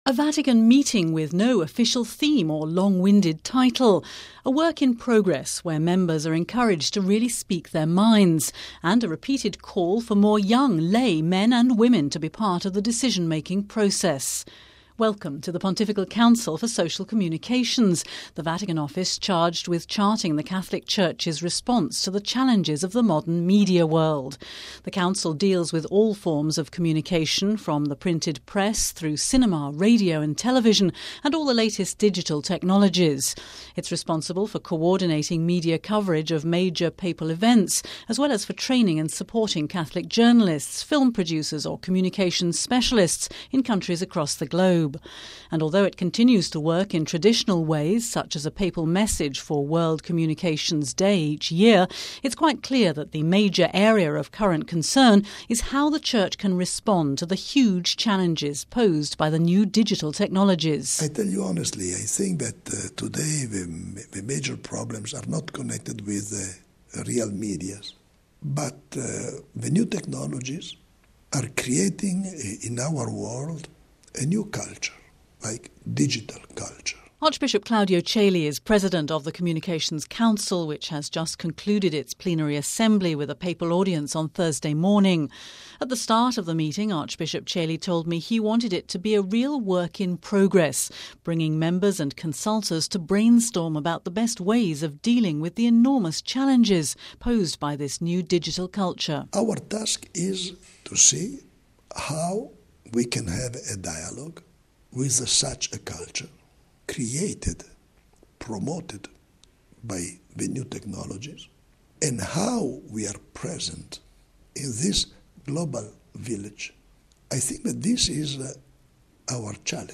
As the Pope meets with the Pontifical Council for Social Communications, we hear from members and advisers about the challenges of communicating the faith in our digital culture..